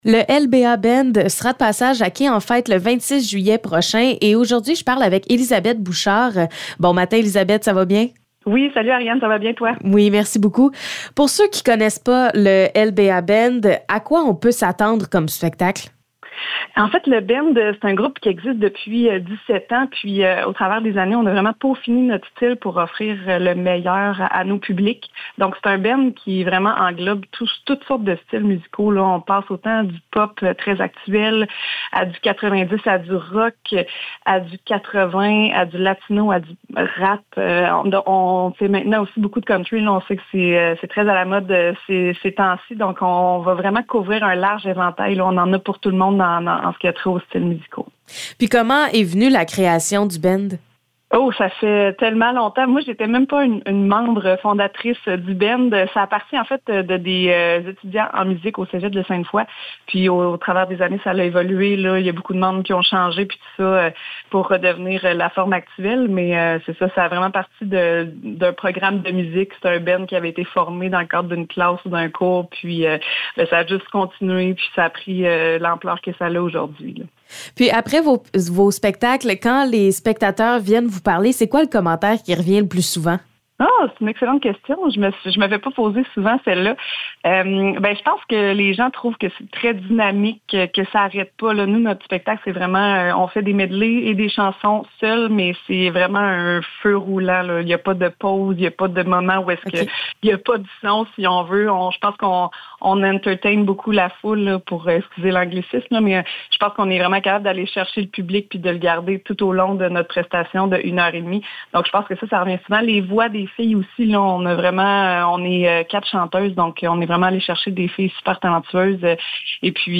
Entrevue avec LBA Band